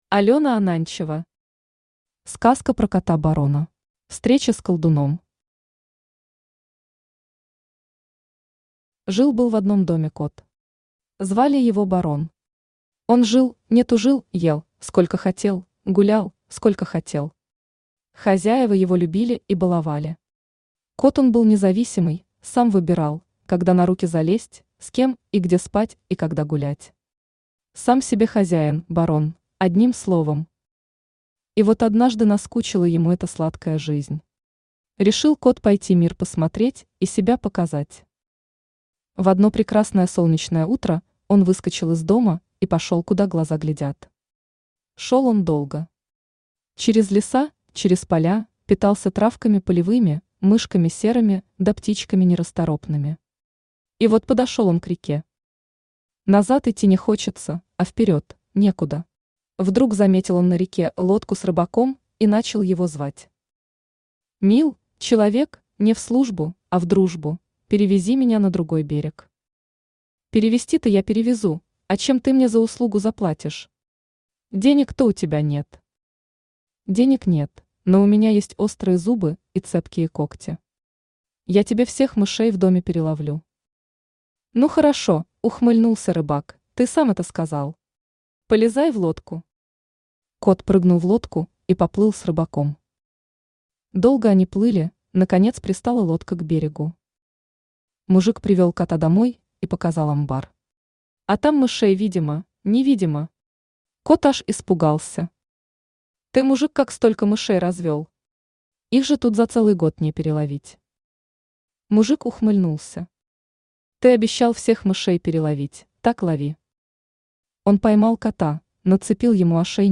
Aудиокнига Сказка про кота Барона Автор Алена Ананчева Читает аудиокнигу Авточтец ЛитРес.